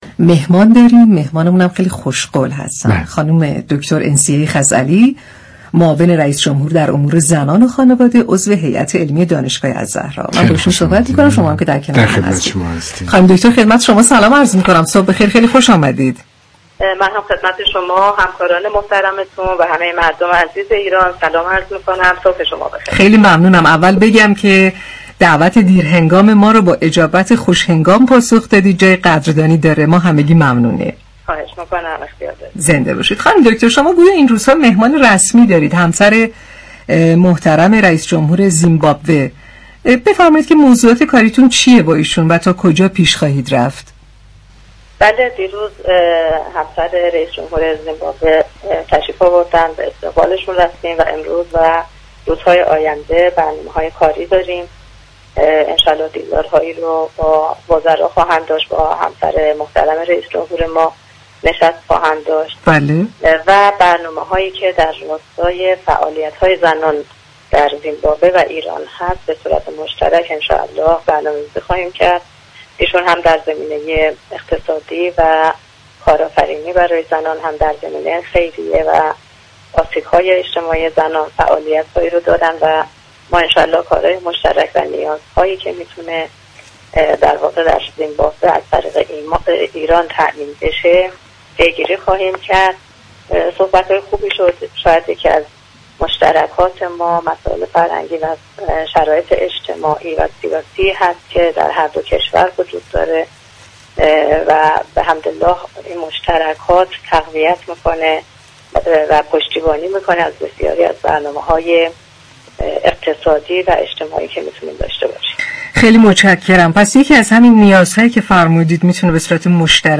دریافت فایل همسر رئیس جمهوری كشور زیمبابوه روز گذشته وارد ایران شد به گزارش پایگاه اطلاع رسانی رادیو تهران، انسیه خزعلی معاون امور زنان و خانواده ریاست جمهوری و عضو هیات علمی دانشگاه الزهرا در گفت‌و‌گو با شهر آفتاب درخصوص سفر همسر رئیس جمهوری كشور زیمبابوه به ایران گفت: روز گذشته 13 آبان ماه ایشان وارد ایران شدند.